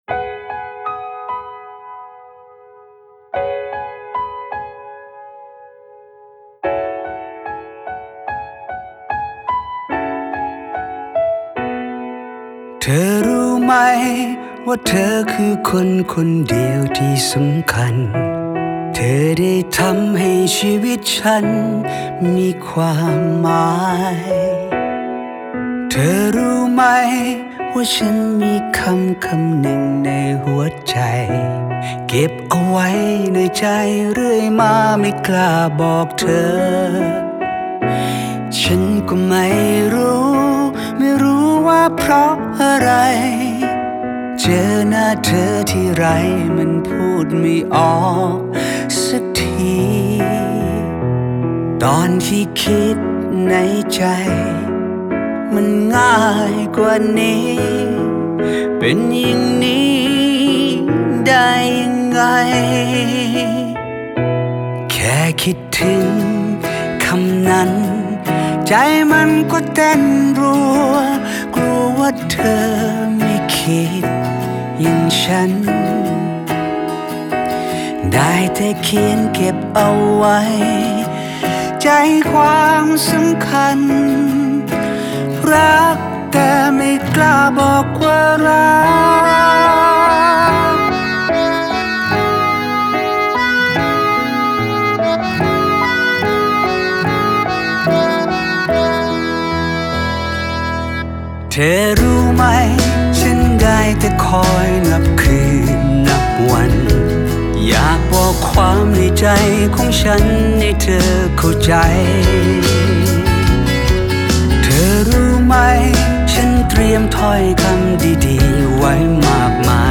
♥ La musique pop ♥